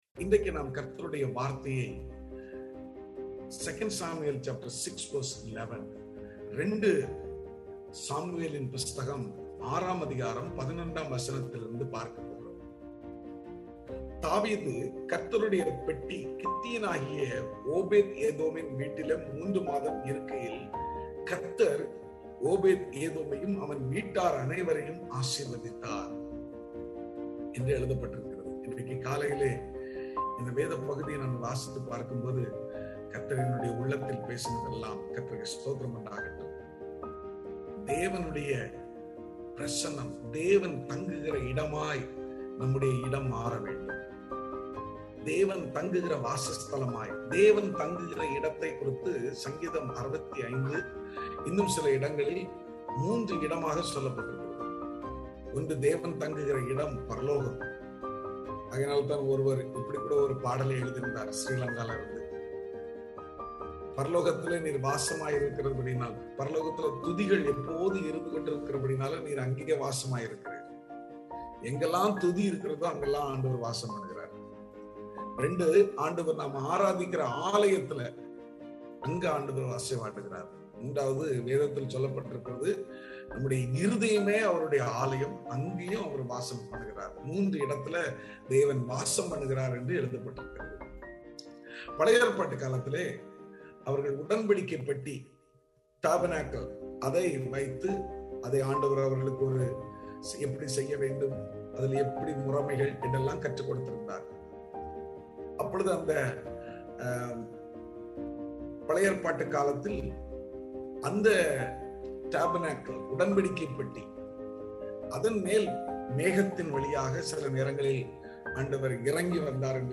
Morning Devotion